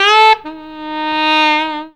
COOL SAX 2.wav